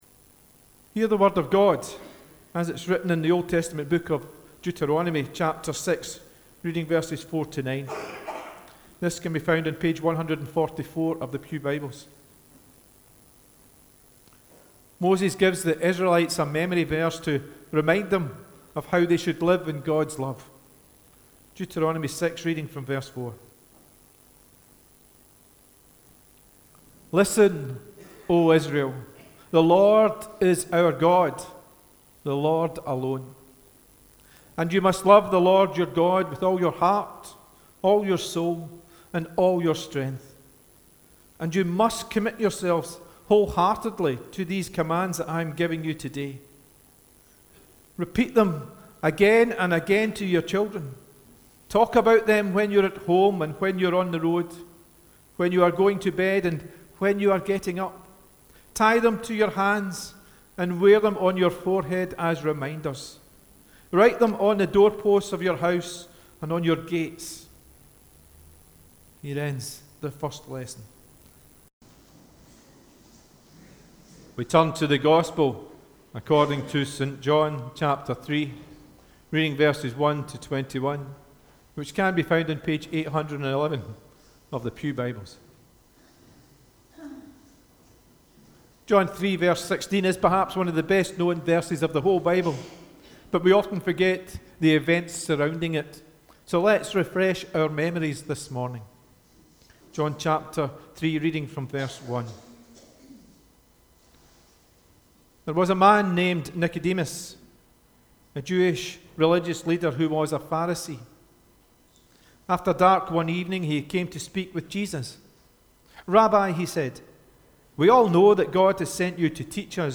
The Scripture Readings prior to the Sermon are Deuteronomy 6: 4-9 and St. John 3: 1-21